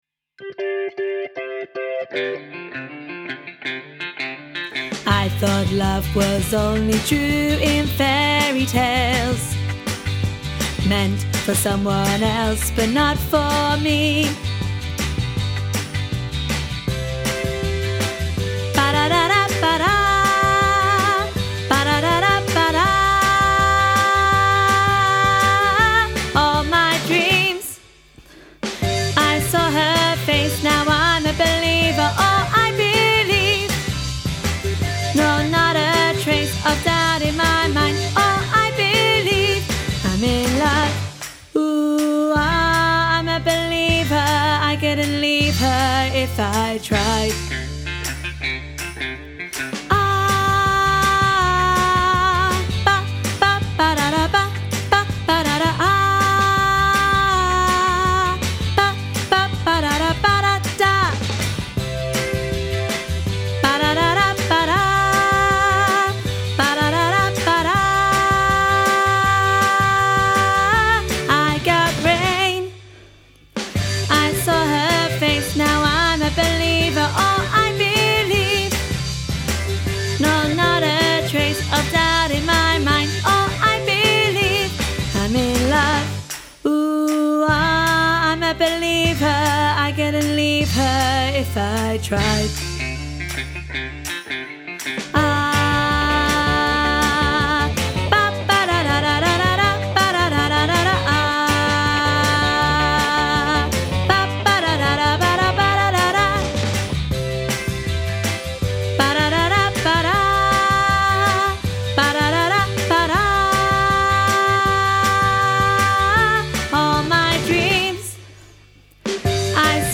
Training Tracks for I'm a Believer
im-a-believer-alto.mp3